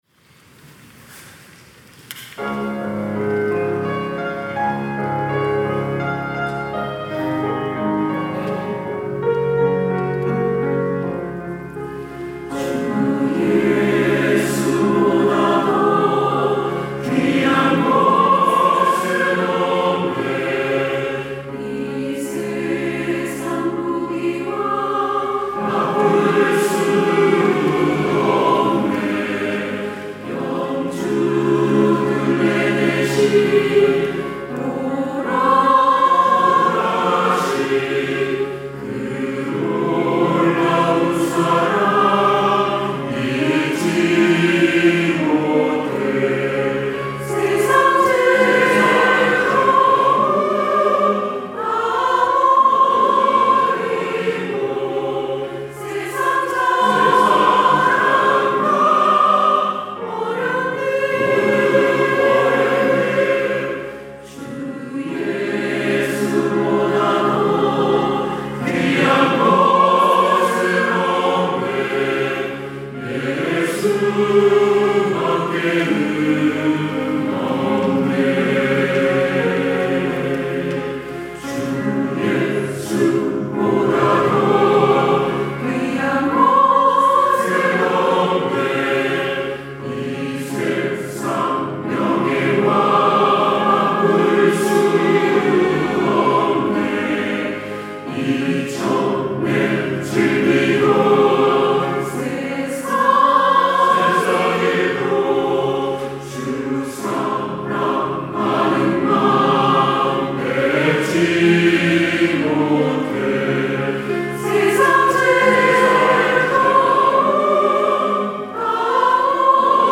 시온(주일1부) - 주 예수보다 더 귀한 것은 없네
찬양대 시온